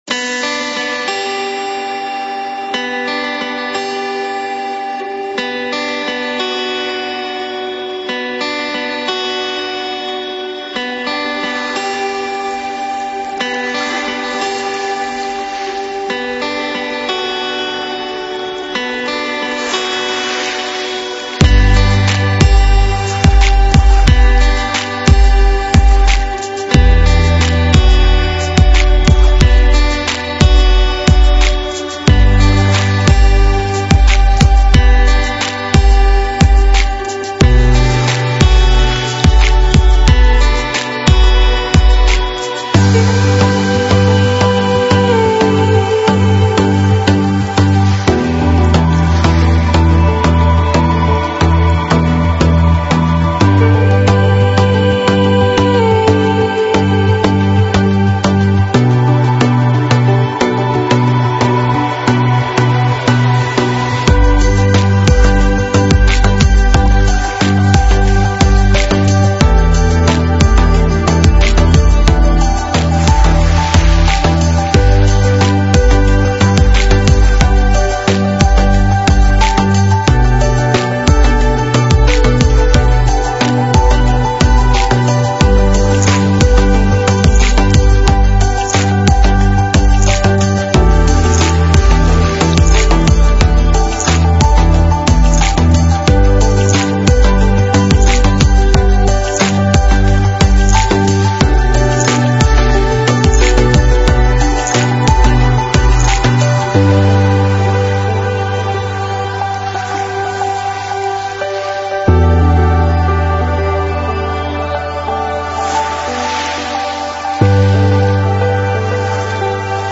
Стиль: Chillout / Lounge / Ambient / Downtempo